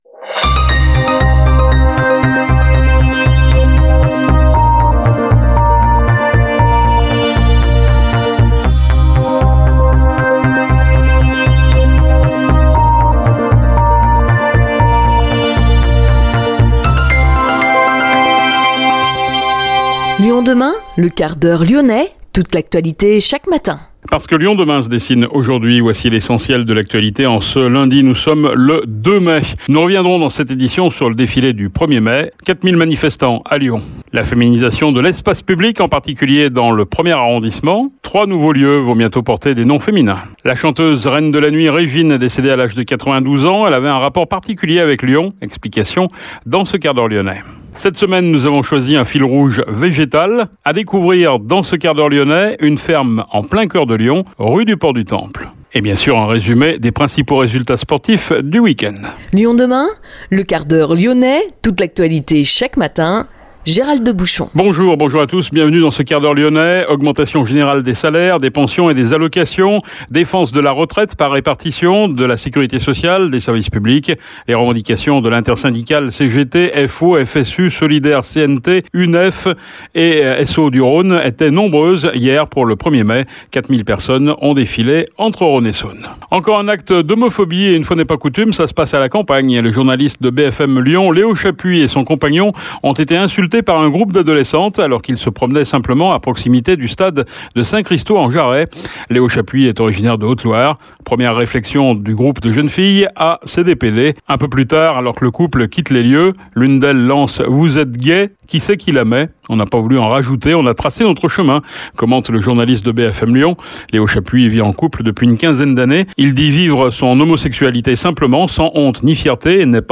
Retrouvez aussi le Quart d’Heure Lyonnais de Lyon Demain sur les antennes de Radio Pluriel (Lyon), Radio Salam (Lyon, Saint-Etienne et Bourg) et Radio Arménie (Lyon et Vienne)